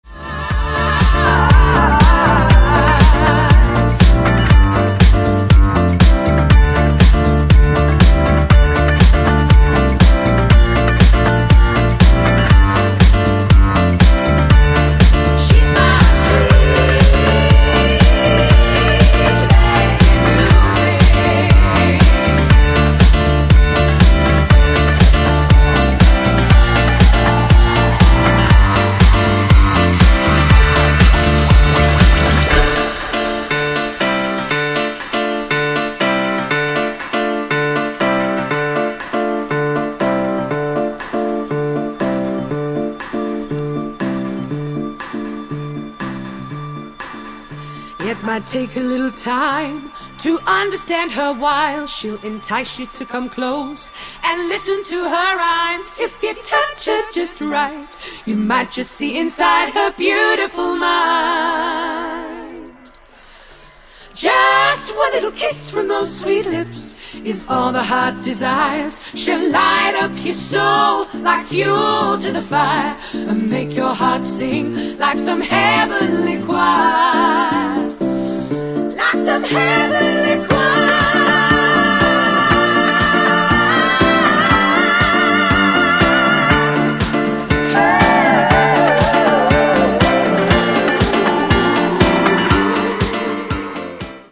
ジャンル(スタイル) NU DISCO / DISCO / HOUSE